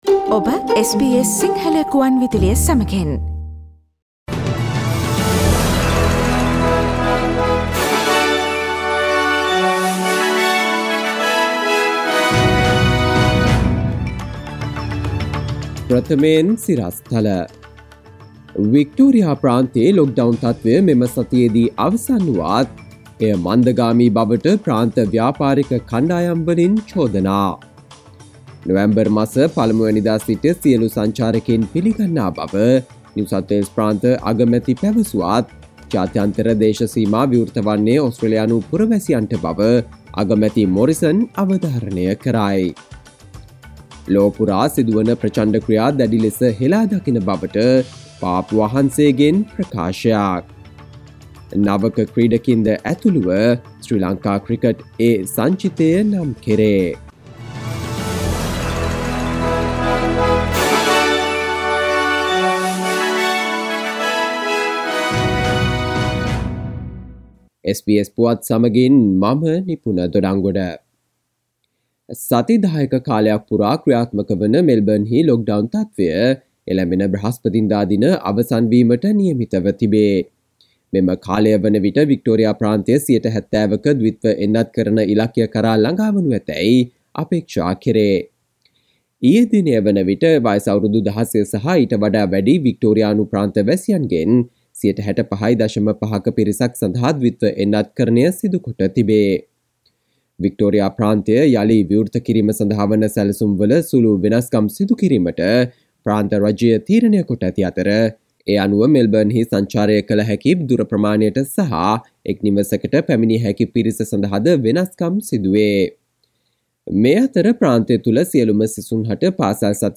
සවන්දෙන්න 2021 ඔක්තෝබර් 18 වන සඳුදා SBS සිංහල ගුවන්විදුලියේ ප්‍රවෘත්ති ප්‍රකාශයට...